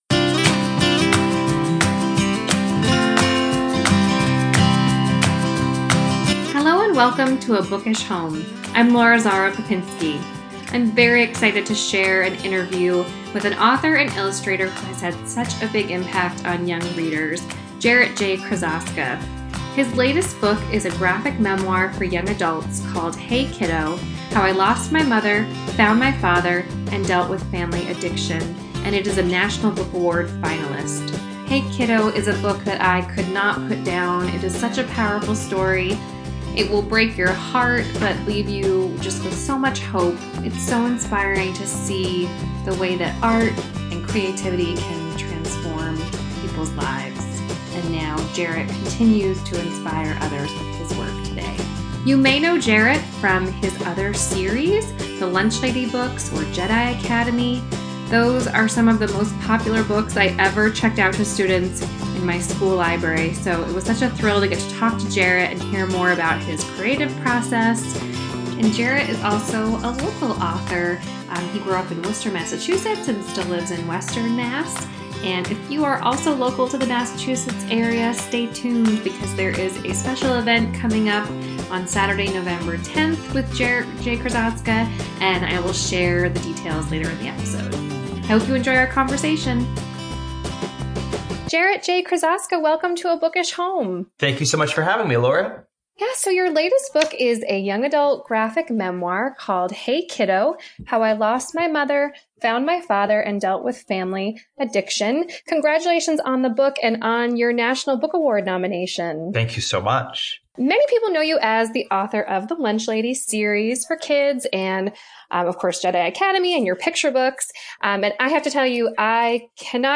This week’s episode features an interview with Jarrett J. Krosoczka.
heykiddointerview_final.mp3